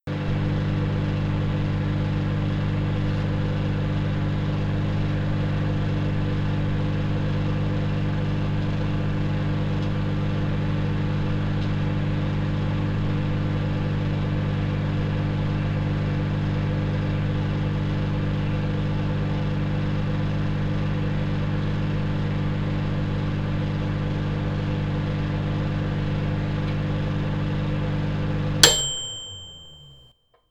microwave cycle with ding 1
bell ding drone electronic hum microwave whir sound effect free sound royalty free Nature